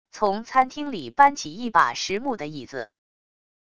从餐厅里搬起一把实木的椅子wav音频